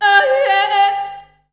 Index of /90_sSampleCDs/Voices_Of_Africa/SinglePhrasesFemale
19_Yodel2.WAV